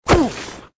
MG_cannon_hit_dirt.ogg